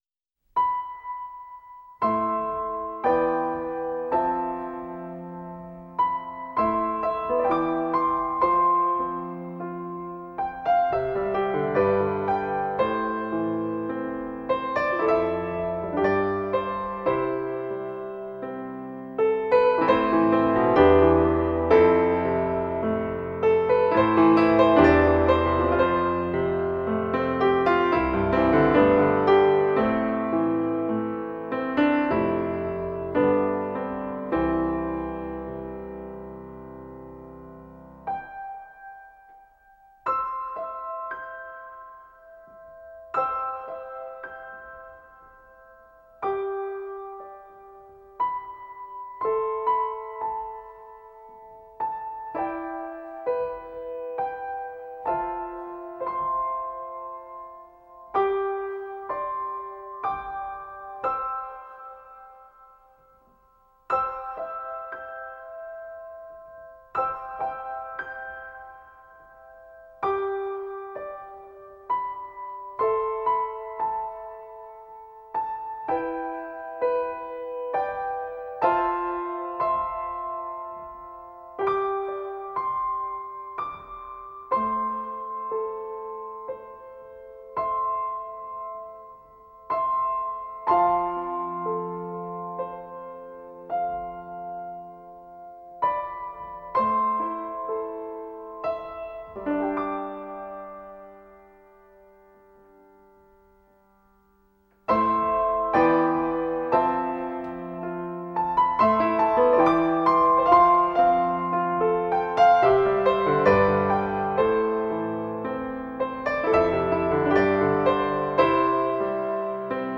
موسیقی اینسترومنتال موسیقی بی کلام
Anime OST